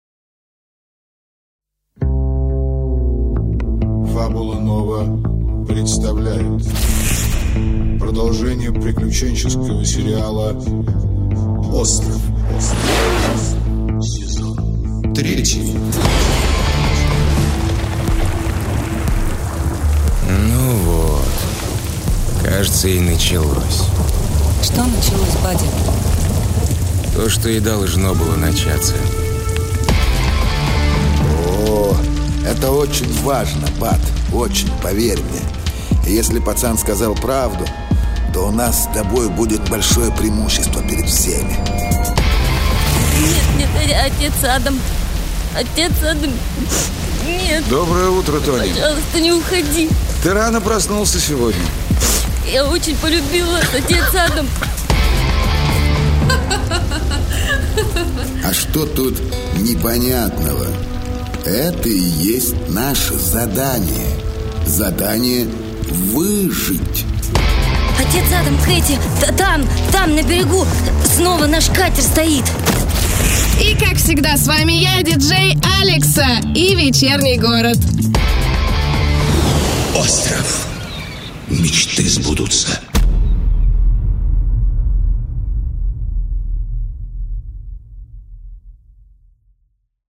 Аудиокнига Остров. Сезон дождей | Библиотека аудиокниг
Сезон дождей Автор Игорь Орлов Читает аудиокнигу Актерский коллектив.